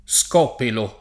[ S k 0 pelo ]